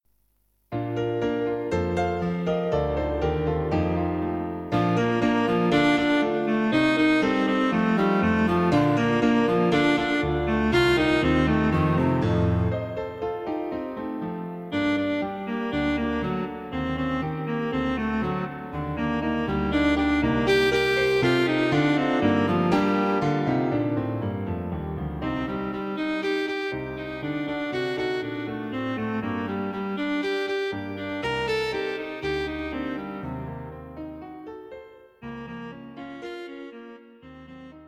Cello and Piano A charming little piece for Cello and Piano.
Plenty of busy rhythms and harmonies to keep it interesting.